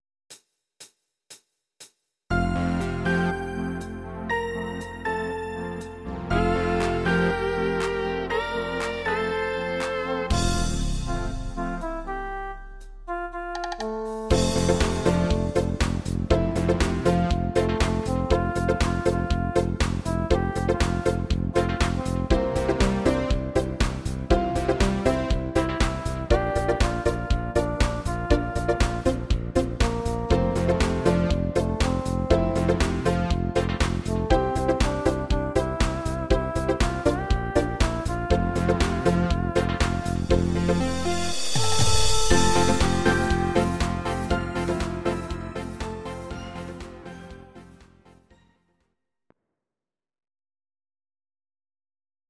Audio Recordings based on Midi-files
Our Suggestions, Pop, German, Medleys, 2020s